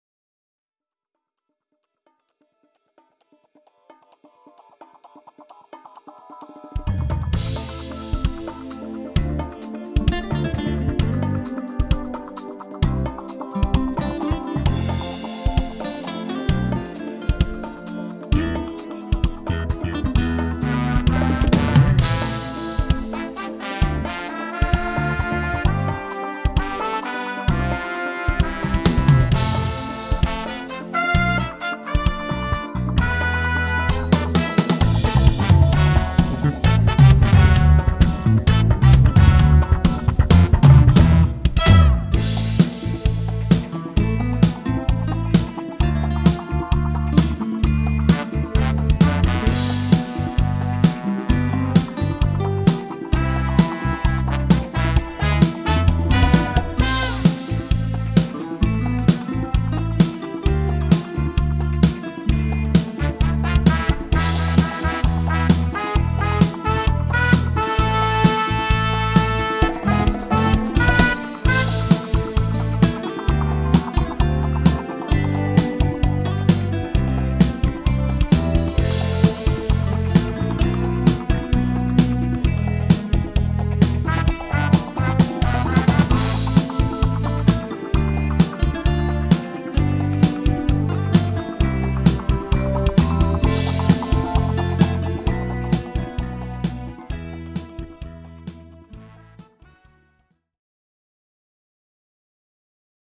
פלייבק שאני עושה כעת.